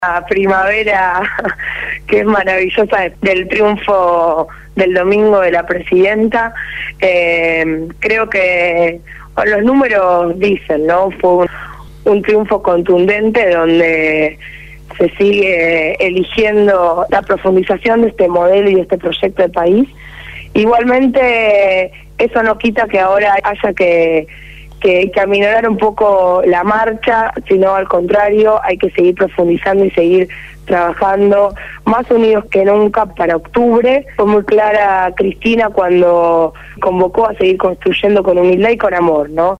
Victoria Colombo, referente de la agrupación La Cámpora y Comunera electa en la Comuna 4 por el Frente Para la Victoria habló en el programa Punto de Partida de Radio Gráfica FM 89.3 con motivo del Festival por el Día del Niño que se realizará a partir de las 14 horas en Iriarte y Vélez Sarfield, en el Barrio de Barracas de la Ciudad de Buenos Aires.